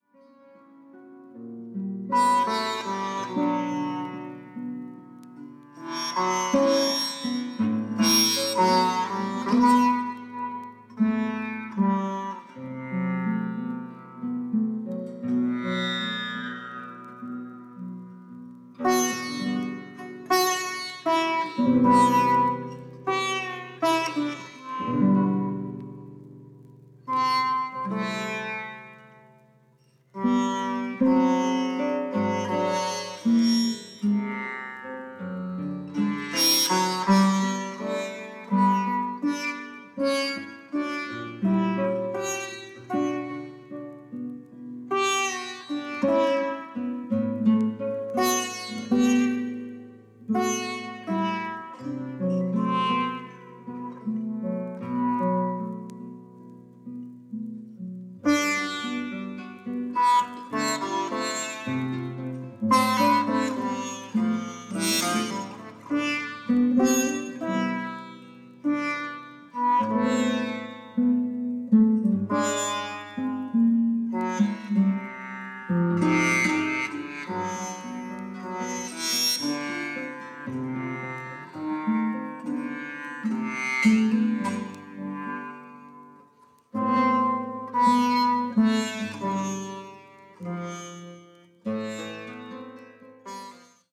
フォーク・ワールドミュージックも取り入れたアプローチが素敵です。